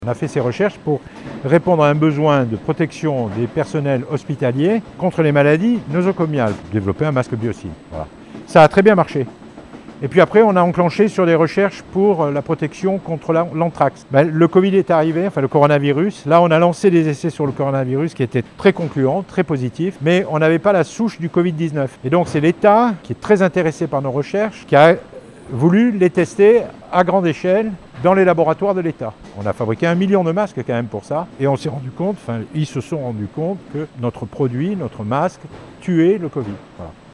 Ils devraient être rapidement mis sur le marché. Visite du site de production à Labarthe-sur-Lèze au sud-ouest de Toulouse.
Reportage Sud Radio